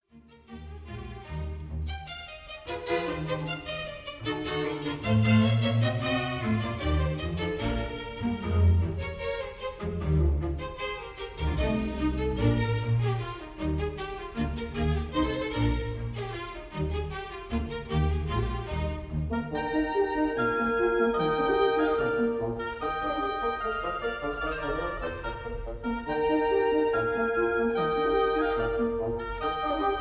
Presto
Recorderd live at the 1st Aldeburgh
Jubilee Hall, Aldeburgh, Suffolk